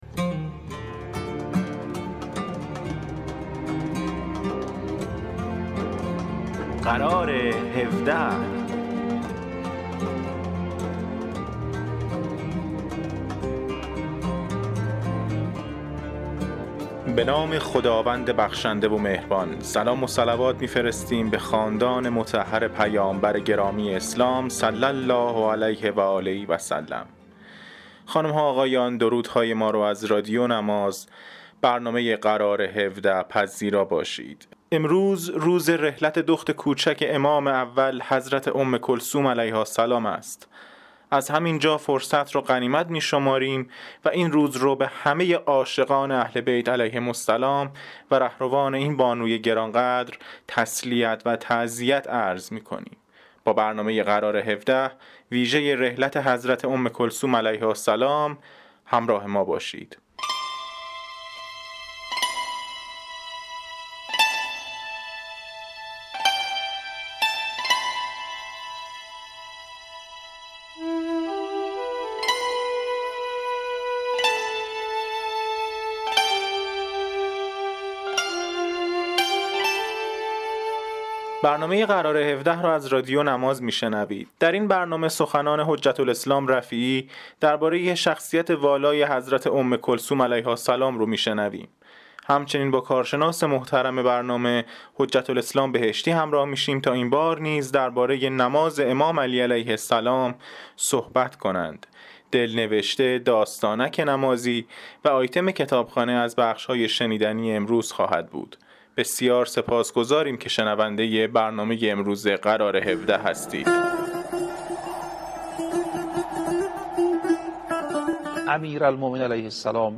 برنامه اینترنتی قرار هفده مجموعه ای از آیتم های نمازی و در مورد بخش های مختلف از نماز، دارای تواشیح، سخنرانی های نمازی، سرود و ترانه، دلنوشته، خاطرات و معرفی کتاب و … است.